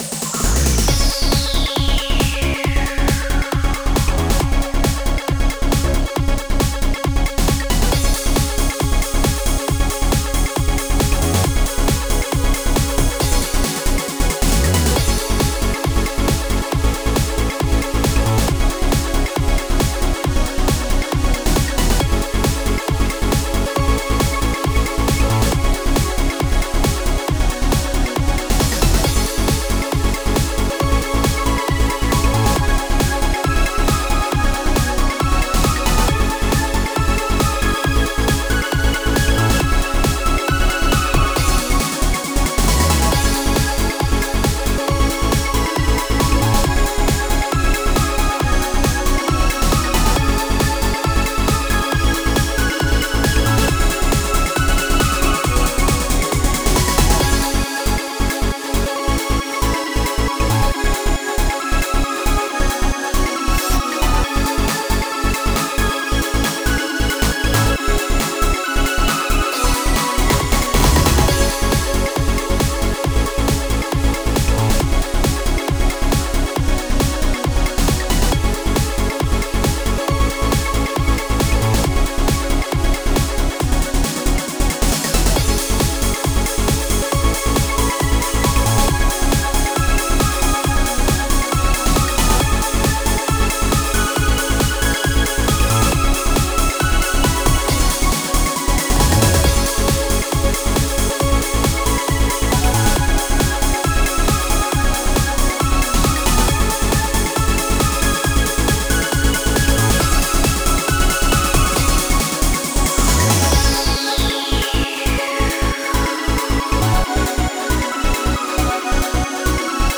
Stil: Trance